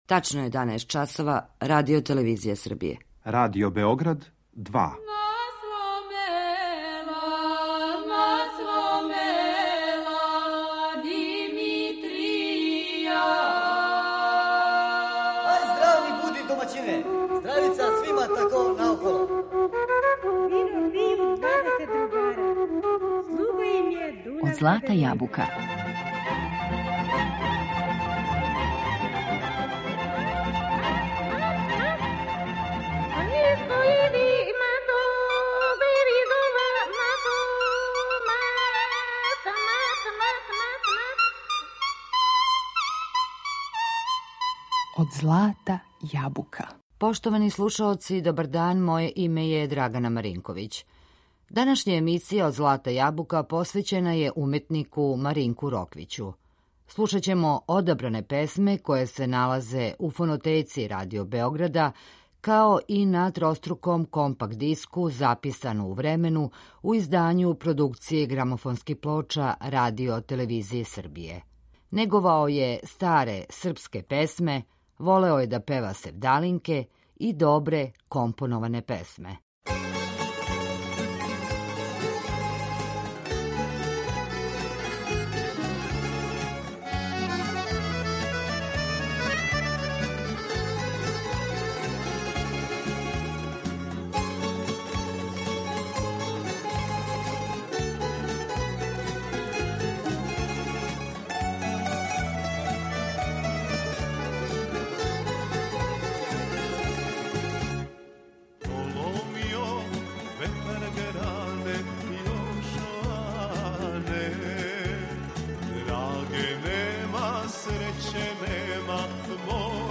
Слушаћемо одабране песме